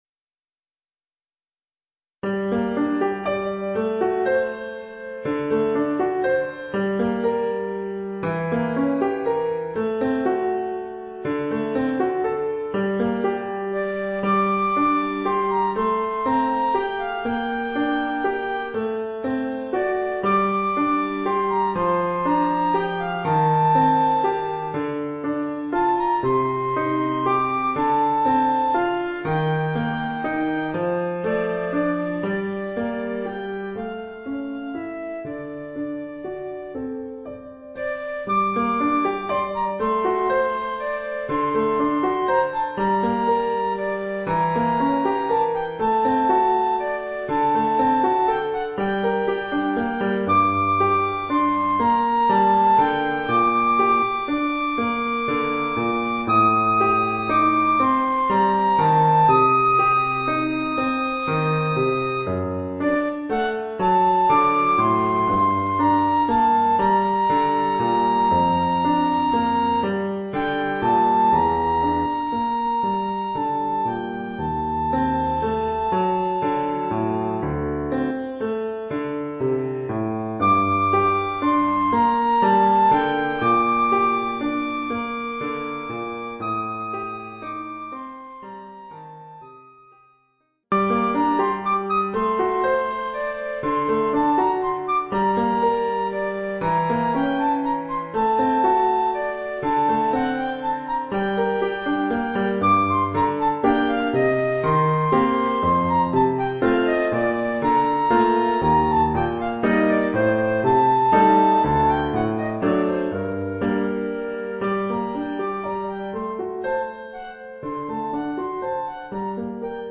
Formule instrumentale : Flûte et piano
Oeuvre pour flûte et piano.